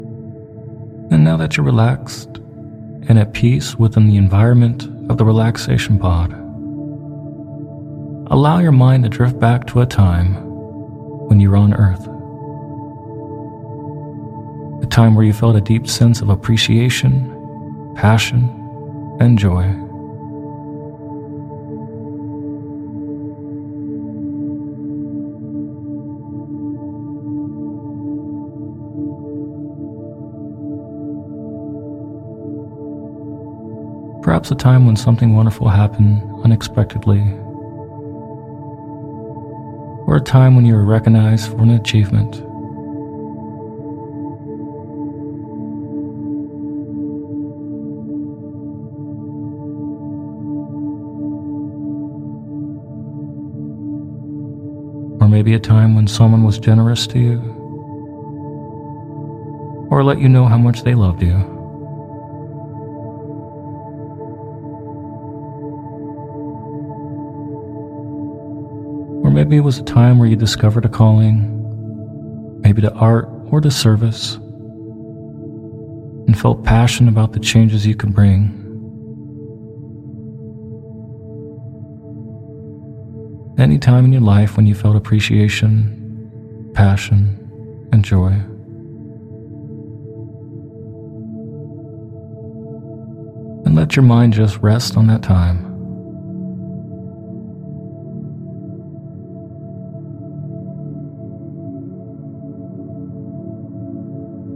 In this guided meditation (or hypnosis audio) you’ll be guided via an interstellar journey to locate “The Abundance Tree” which may help you to manifest more abundance and wealth in your life.